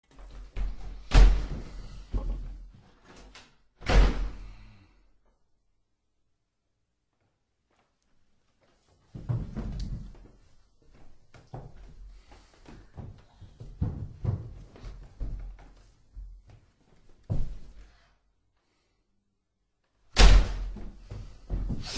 Эта страница предлагает коллекцию звуков, воссоздающих атмосферу психиатрической больницы: отдаленные голоса, шаги по пустым коридорам, металлический лязг решеток.
Звуковая стереоатмосфера психиатрического учреждения для пациентов с расстройствами психики (3D)